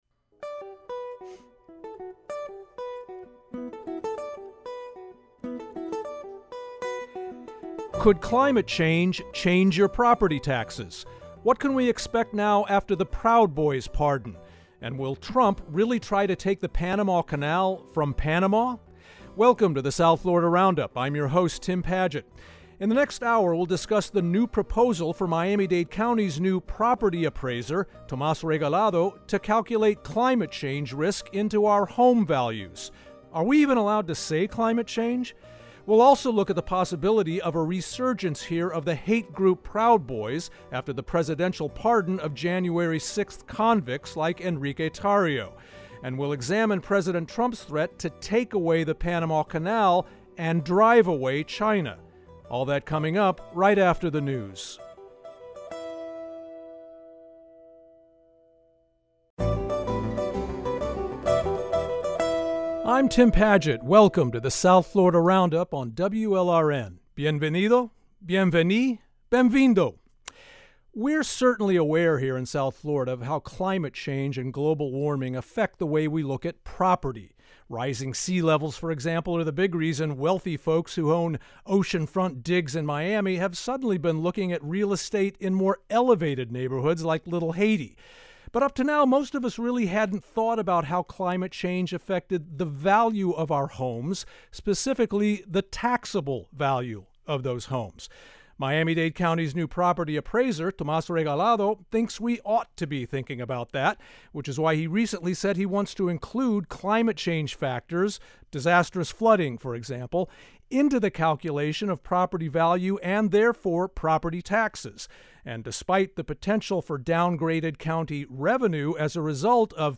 Each week, journalists and newsmakers from South Florida analyze and debate some of the most topical issues from across the region.